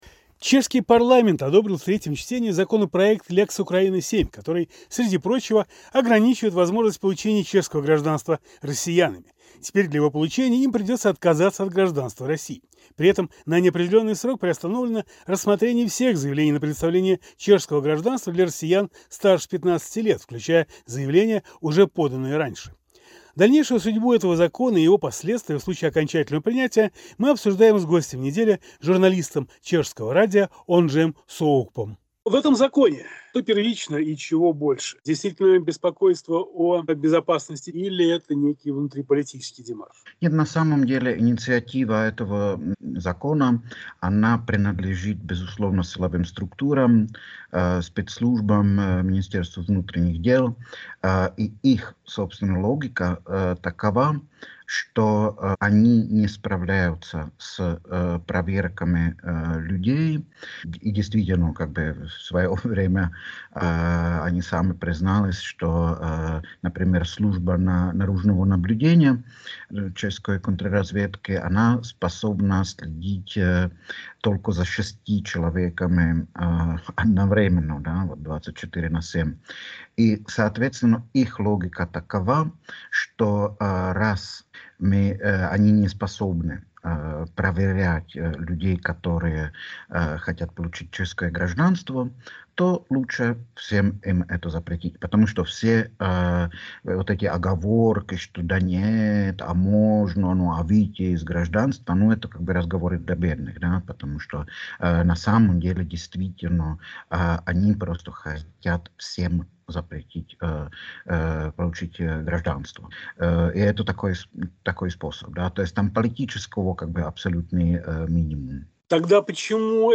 Гость недели – чешский журналист